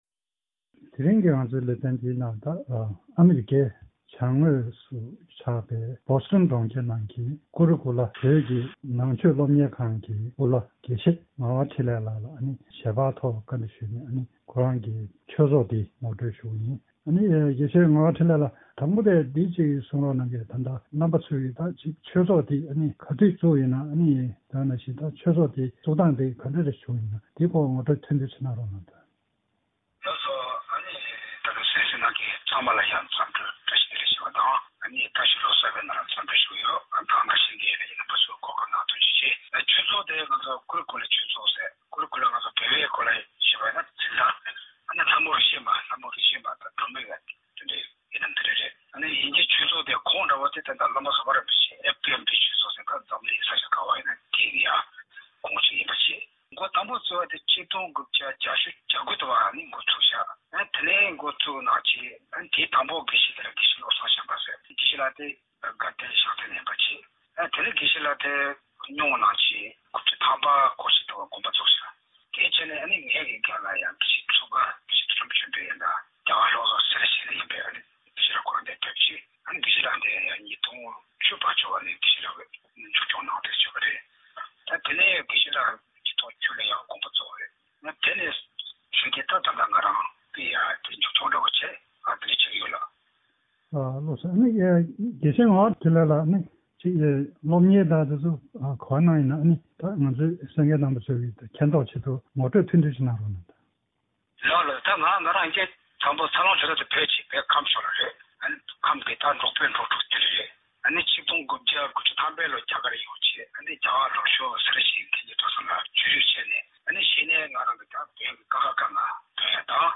གནས་འདྲི་ཞུས་པ་ཞིག་གཤམ་ལ་གསན་རོགས་གནང་།